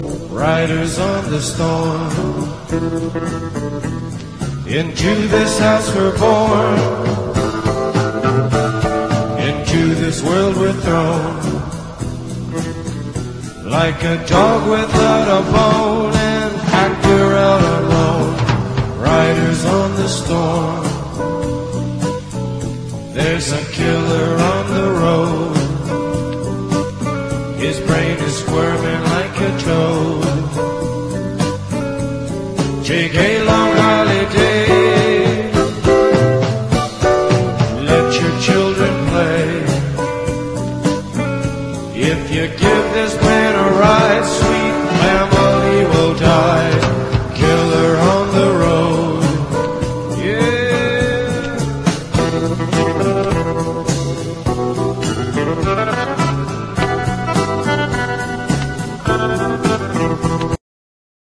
JAZZ / OTHER / A.O.R. / FREE SOUL / CROSSOVER / JAZZ ROCK